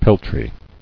[pel·try]